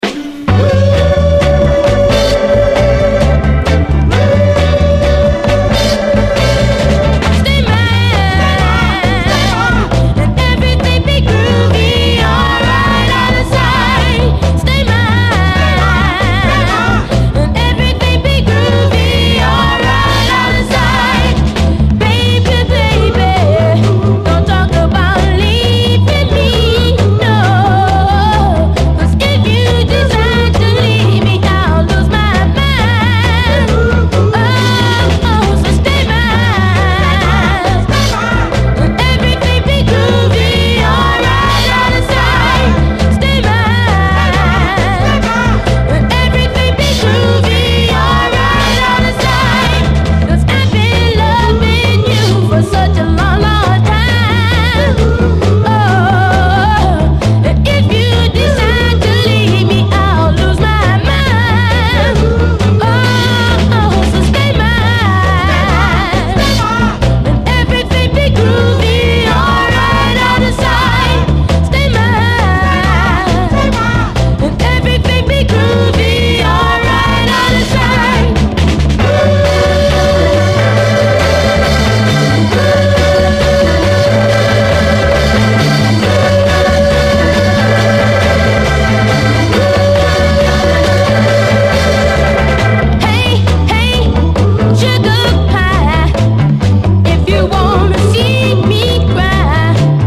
60's SOUL, SOUL, 70's～ SOUL, 7INCH
最高キッズ・ノーザン・ソウル45！